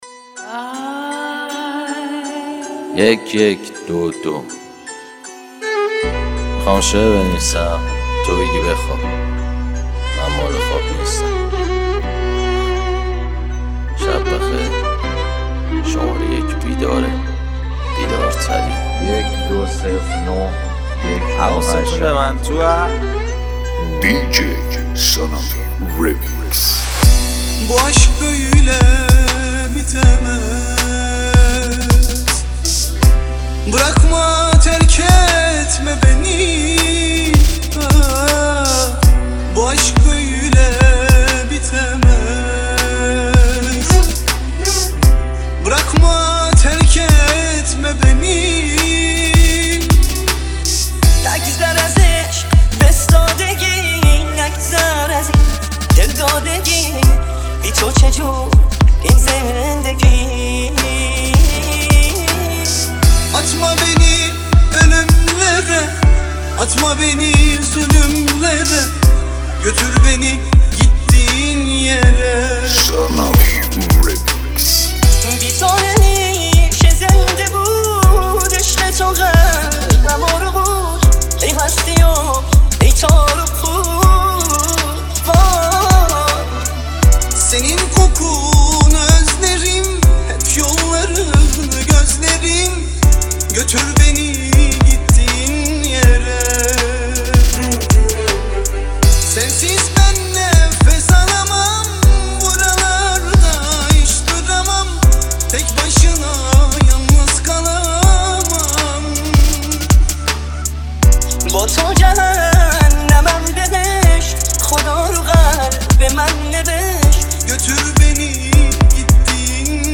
دانلود ریمیکس جدید